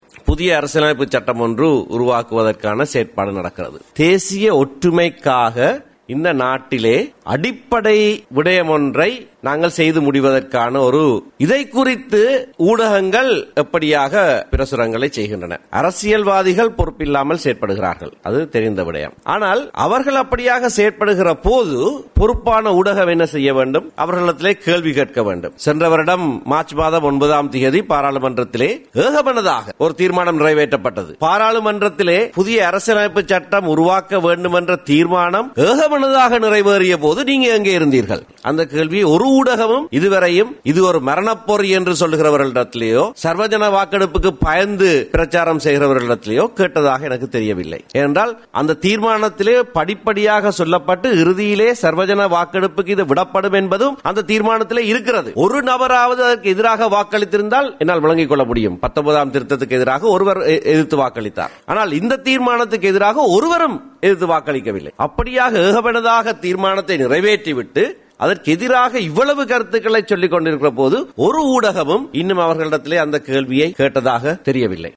அரச தகவல் திணைக்களத்தில் இன்று இடம்பெற்ற ஊடக சந்திப்பில் தமிழ் தேசிய கூட்டமைப்பின் ஊடக பேச்சாளர் எம் ஏ சுமந்திரன் இதனை தெரிவித்தார்.